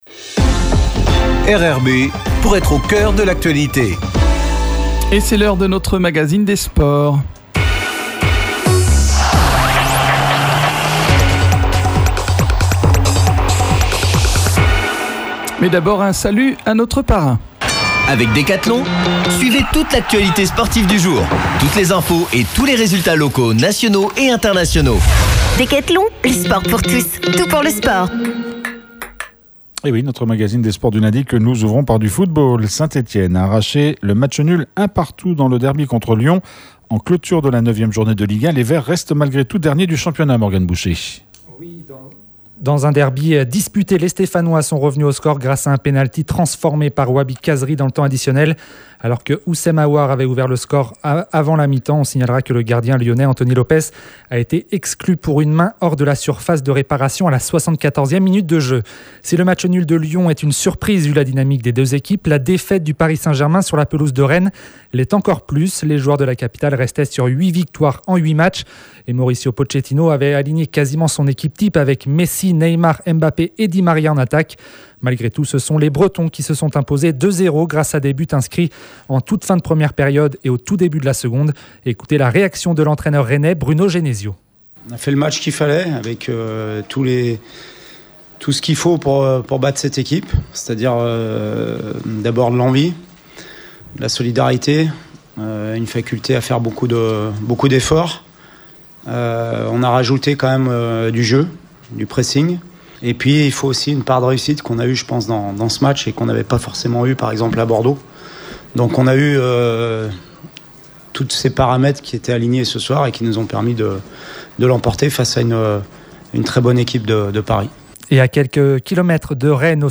On a parlé cyclisme avec notre invité.